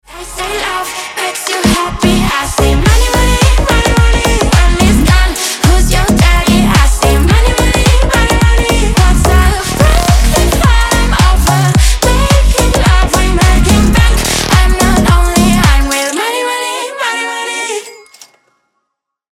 House Басы Качающие
Женский голос Slap house